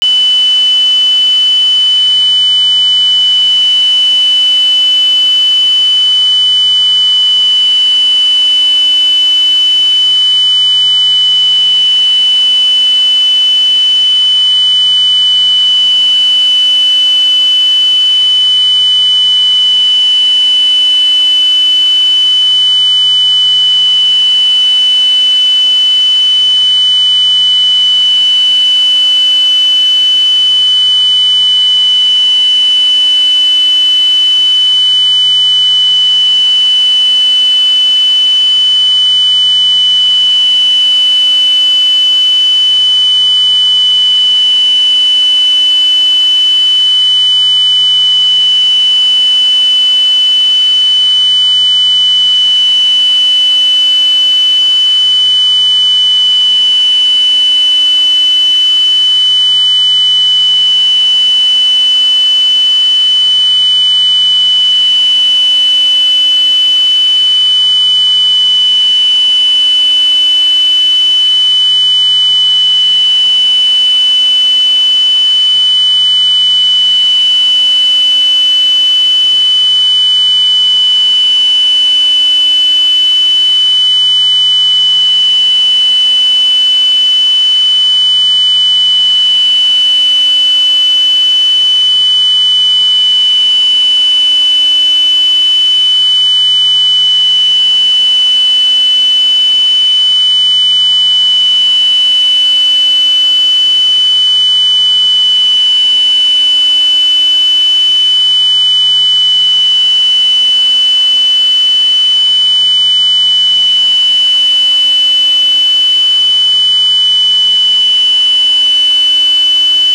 Timecode-Schallplatte auslesen
• ▲ ▼ Hier mal ein Audiosample vom "Mixvibes" System.
Das Sample kommt digital von der CD.